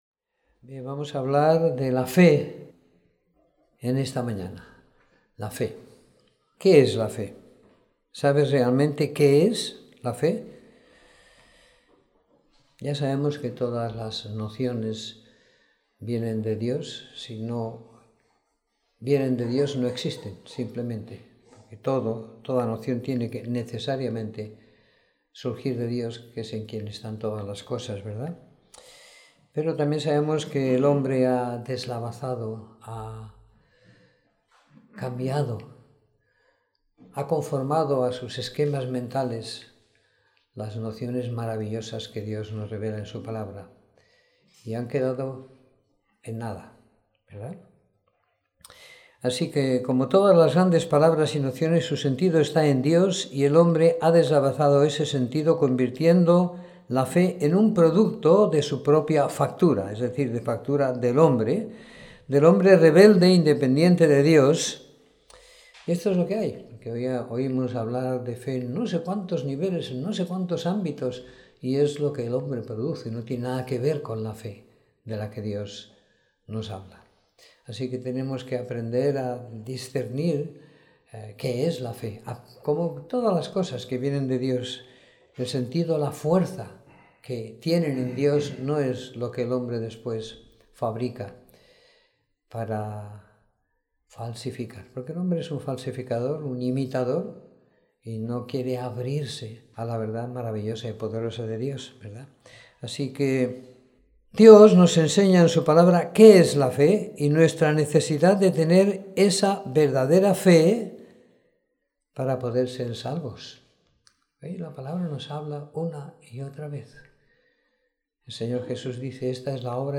Domingo por la Mañana . 03 de Julio de 2016